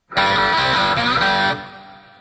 skull guitar
skull-guitar.mp3